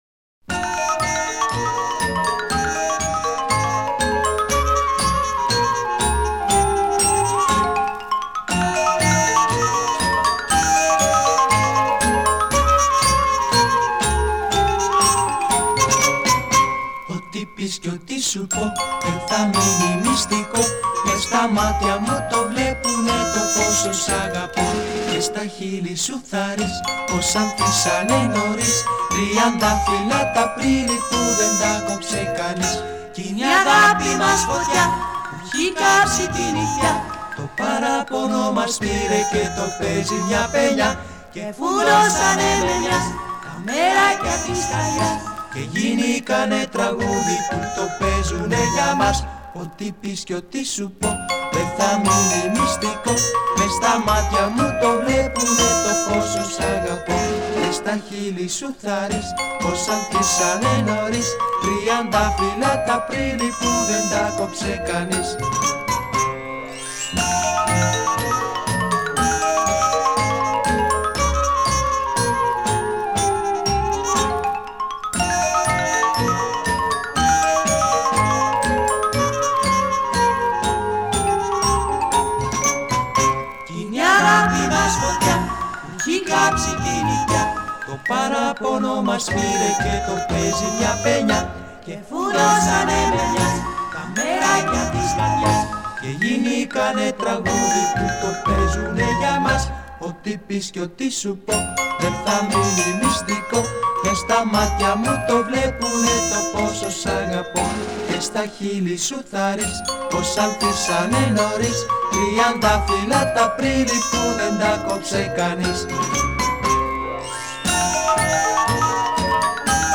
Танго по гречески